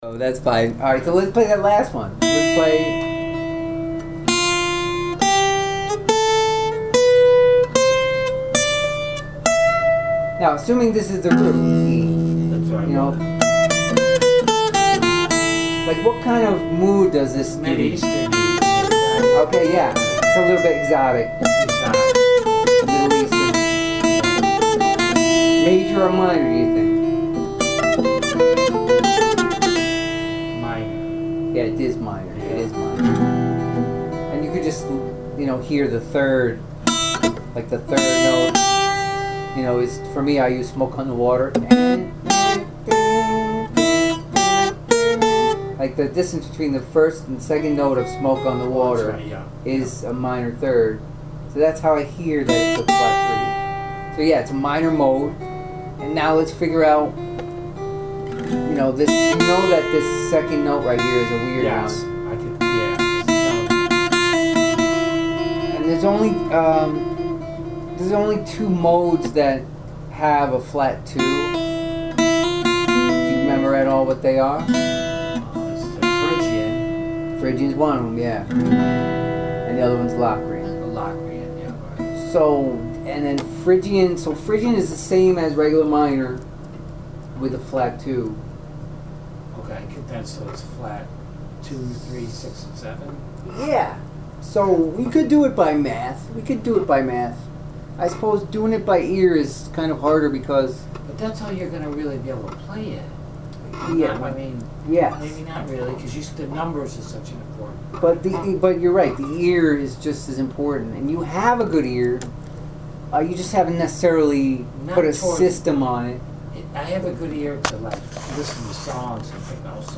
sing-along ear training practice – 7 modes in Bb
live class